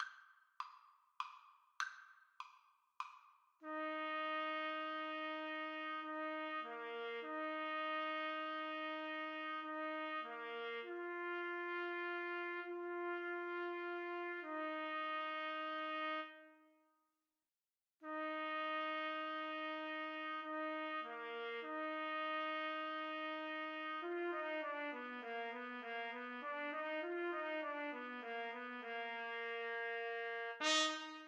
3/4 (View more 3/4 Music)
Trumpet Duet  (View more Easy Trumpet Duet Music)
Classical (View more Classical Trumpet Duet Music)